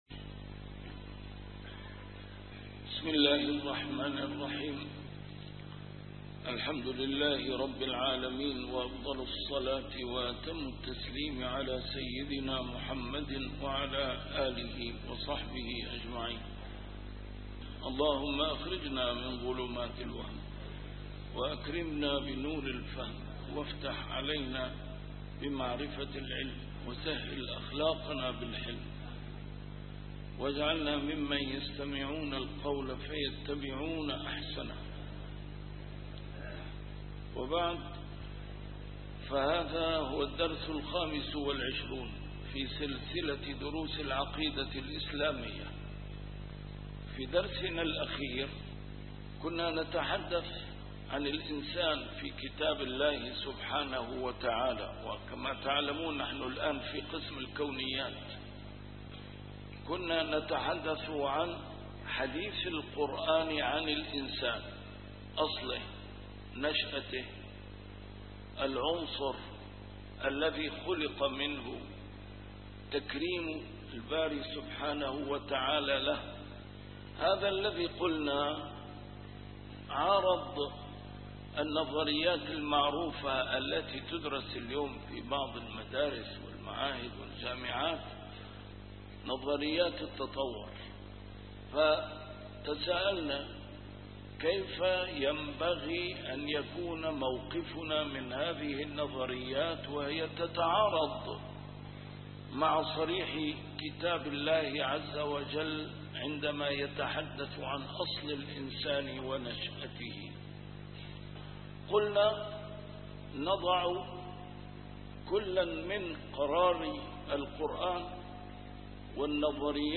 A MARTYR SCHOLAR: IMAM MUHAMMAD SAEED RAMADAN AL-BOUTI - الدروس العلمية - كبرى اليقينيات الكونية - 25- لماذا ينتقدون سائر النظريات التفصيلية، ثم يتبنون فكرة التطور في الجملة + الملائكة